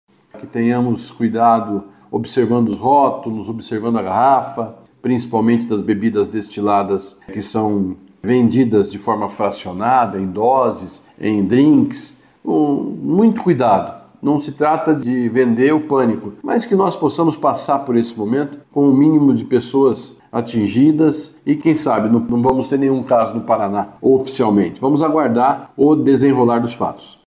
Beto Preto disse que o momento apresenta um dilema e reforçou os cuidados ao ingerir bebida alcoólica.